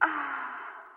aah.mp3